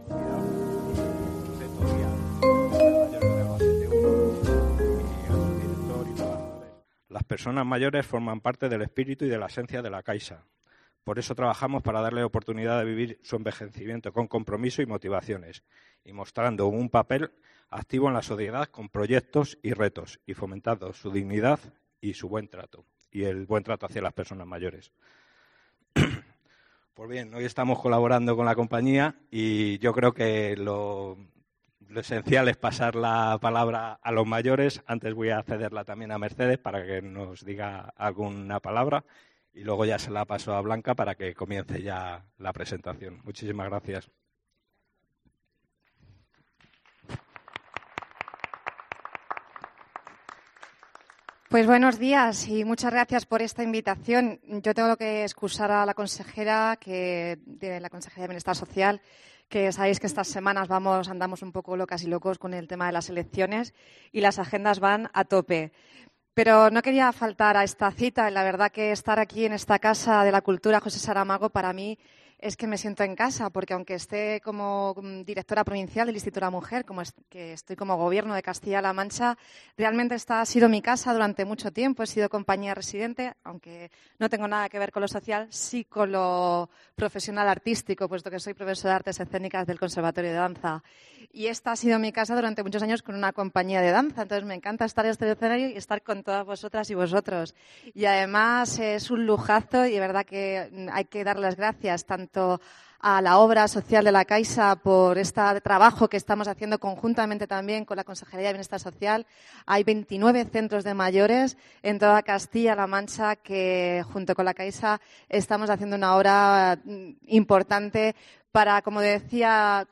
En el audio de la presentación del proyecto en Albacete